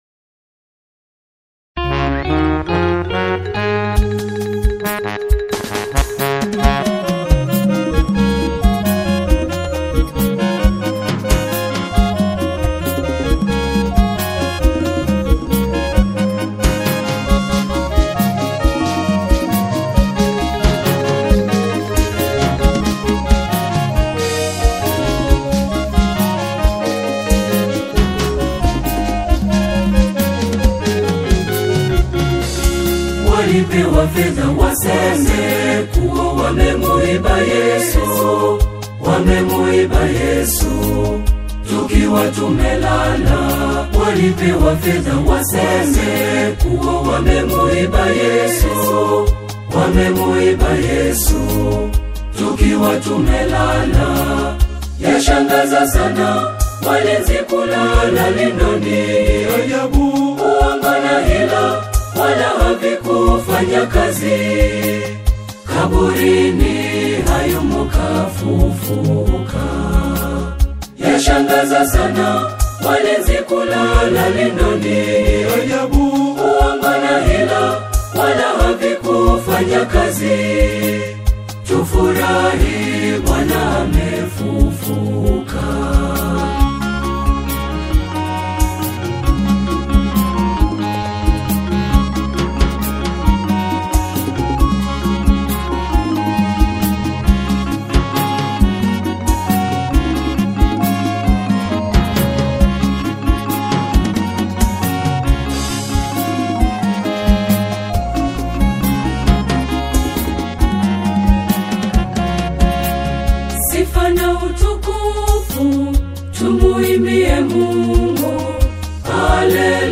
gospel
vocal ensemble
a fresh, contemporary energy to traditional choral themes